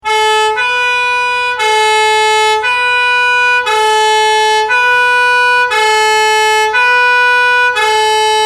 P O L I C E
french-police-siren_24910.mp3